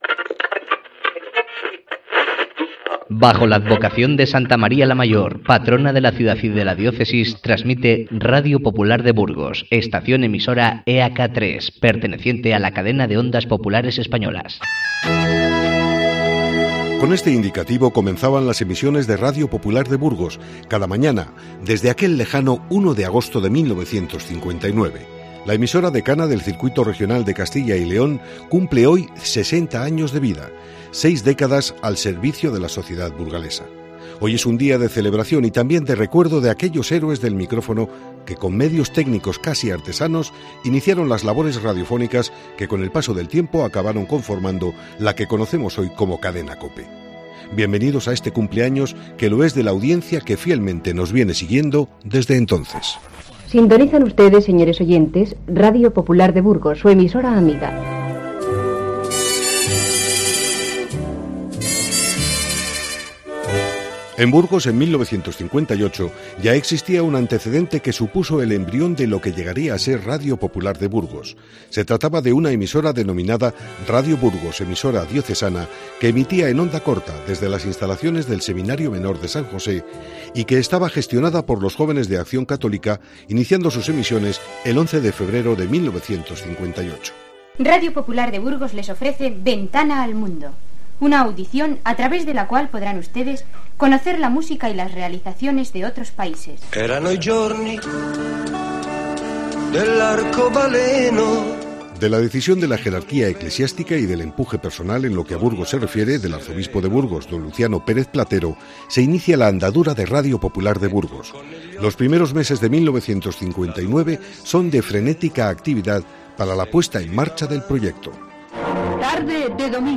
El 1 de agosto de 1959, esta emisora inició sus emisiones regulares. Seis décadas que te resumimos con los sonidos de estos años
60 años de COPE Burgos. Los sonidos de la memoria